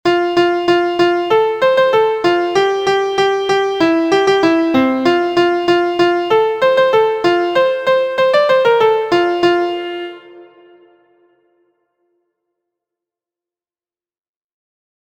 Introducing triplets and multiple sixteenth variations.
• Origin: Kentucky, USA – Singing Game
• Key: F Major
• Time: 2/4